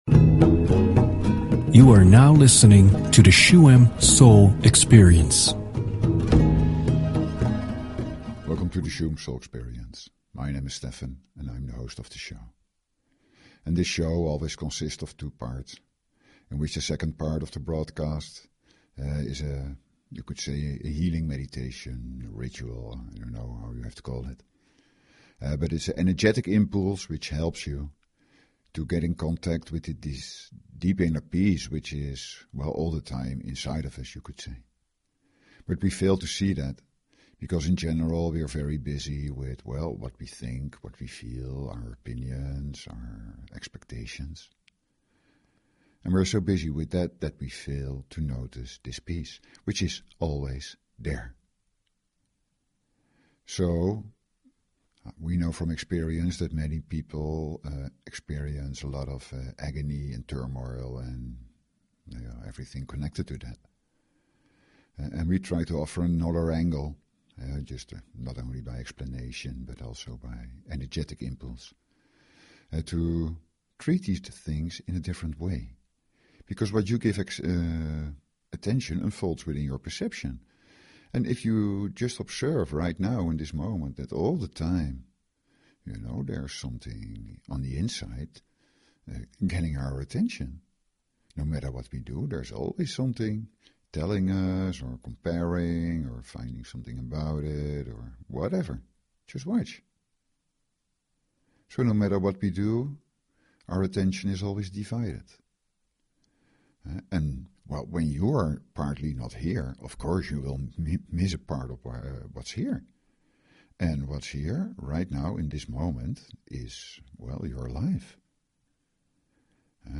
Talk Show Episode, Audio Podcast, Shuem_Soul_Experience and Courtesy of BBS Radio on , show guests , about , categorized as
The second part of the show is a shamanic Healingmeditation.
Listen to it through headphones and let the sounds of drum, rattle and chant flow through you.